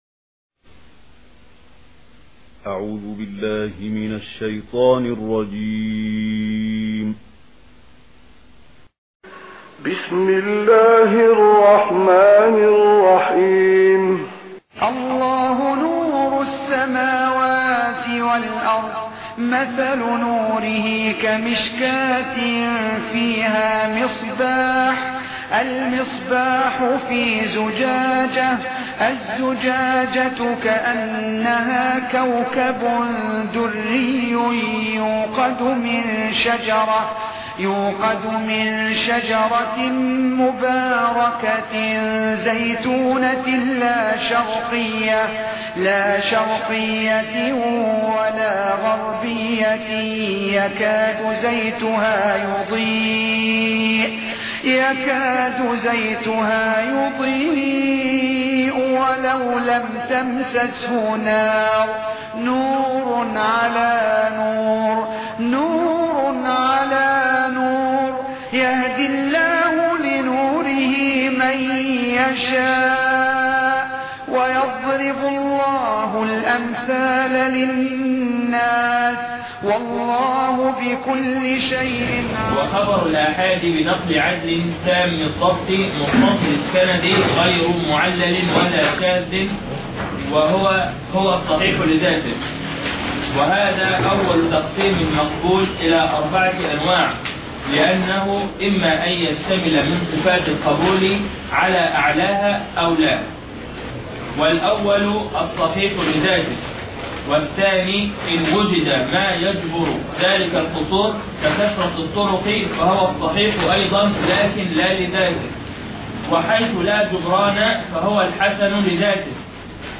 الدرس 10 ( شرح كتاب نزهة النظر )